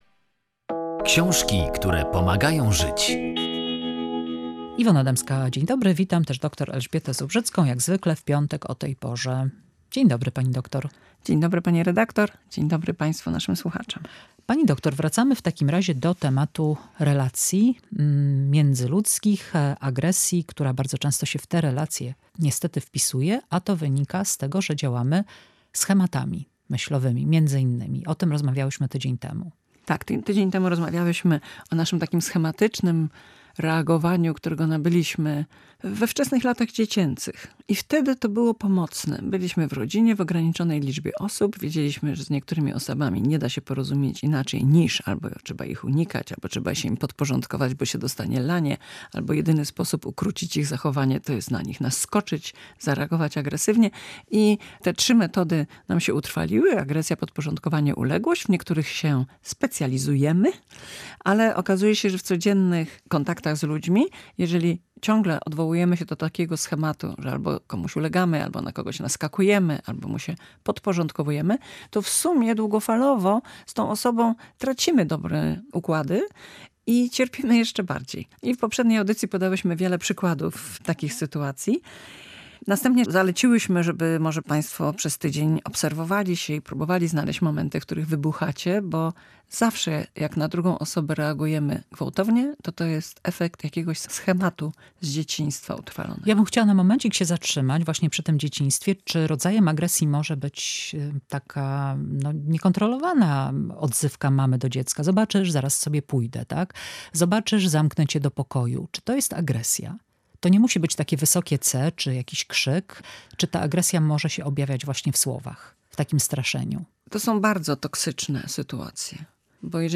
Jak komunikować się bez agresji? Rozmowa w oparciu o książkę "Relacje na huśtawce"